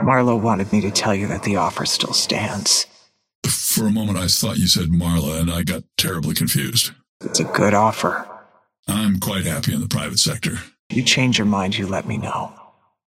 Dynamo and Haze conversation 1